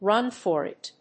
アクセントrún for it